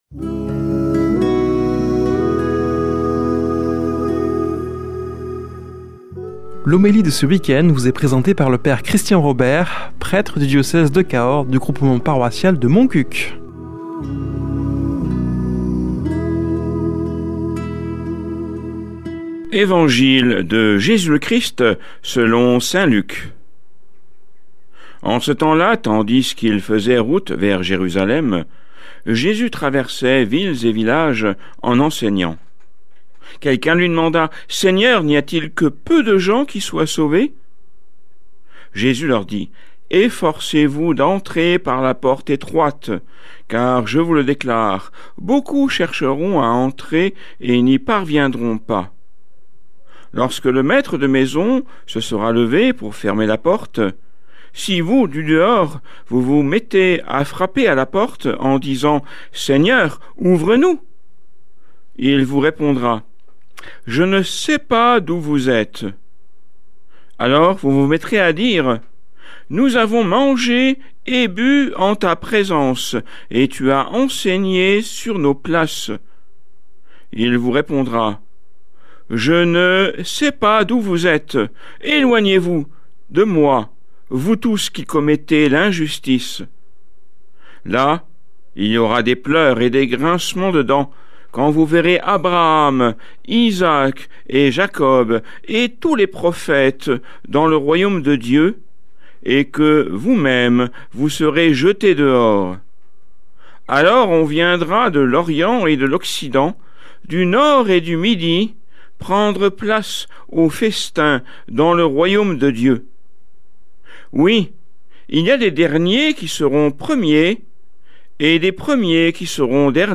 Homélie du 23 août